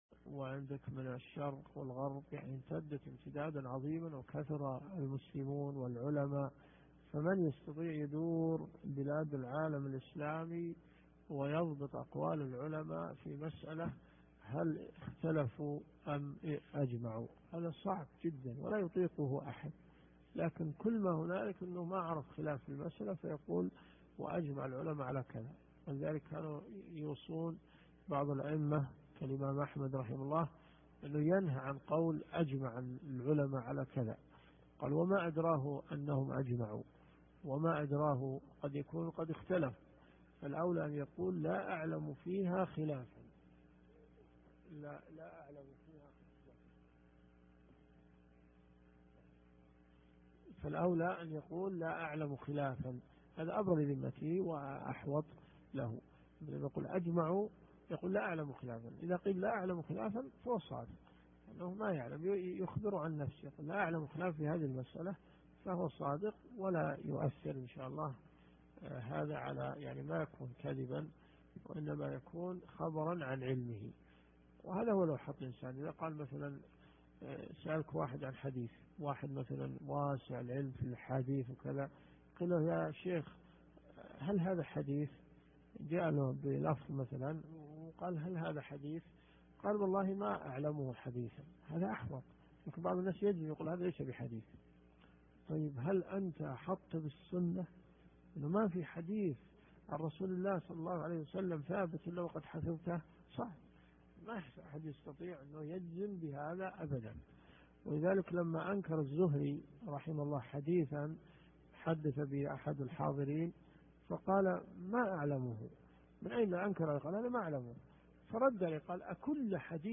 العقيدة الواسطية . من ص 159 قوله ثم هم مع هذه الأصول .... على ما توجبه الشريعة . الدرس في الدقيقة 3.45 .